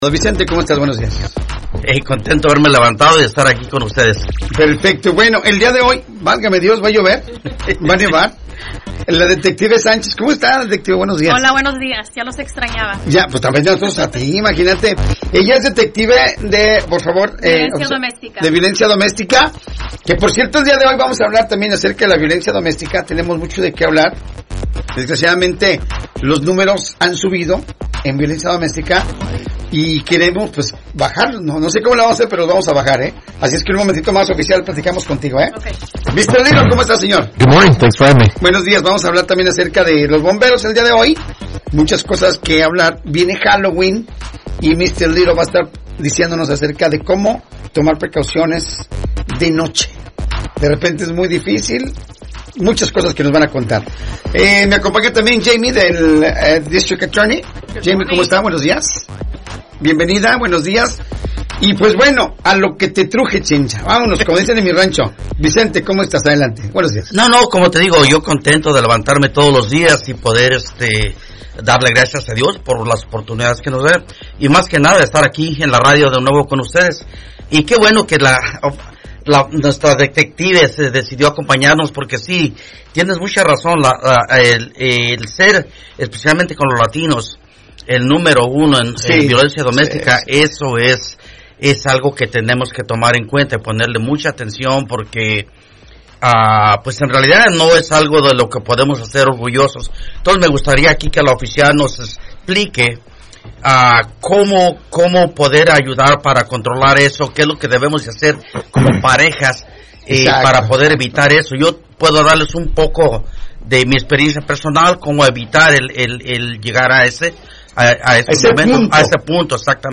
En esta nueva edición, los oficiales compartieron recomendaciones clave para mantenernos seguros durante esta temporada: